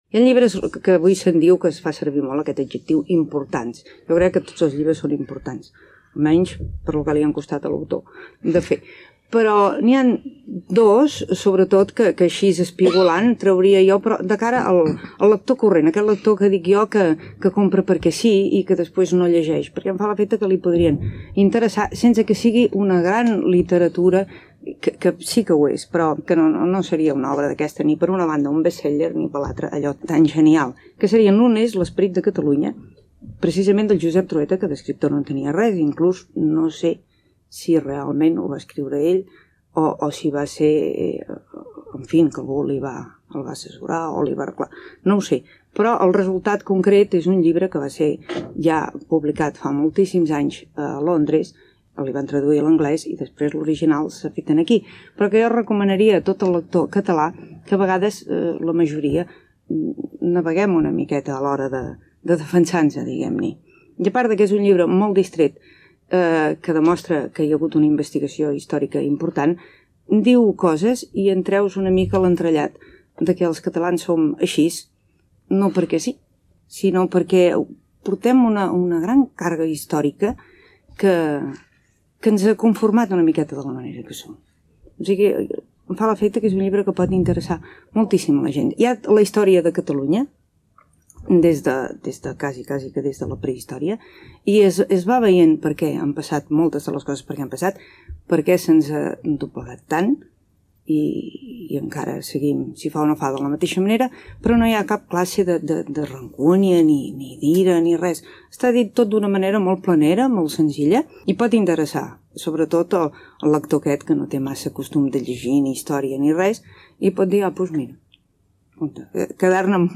Comentari literari sobre els llibres "L'esperit de Catalunya", escrit pel doctor Josep Trueta, i "El llibre del retorn" de Xavier Benguerel
FM